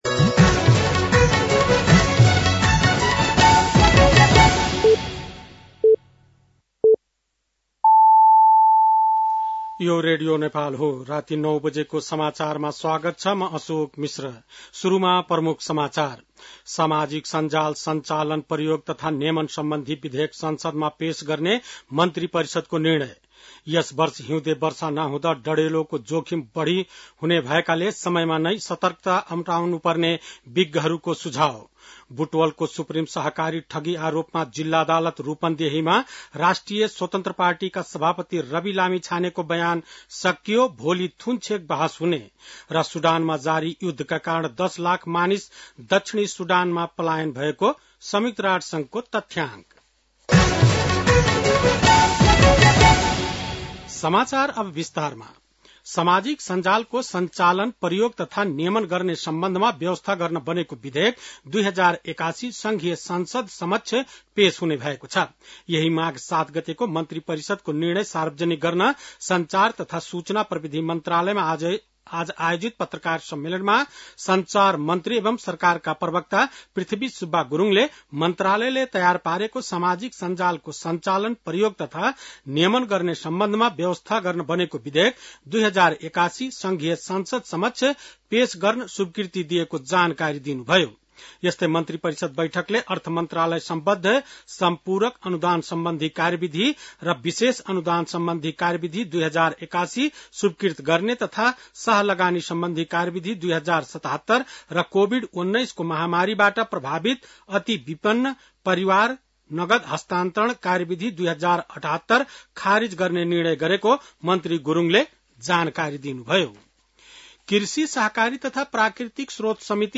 बेलुकी ९ बजेको नेपाली समाचार : ११ माघ , २०८१
9-PM-Nepali-NEWS-10-09.mp3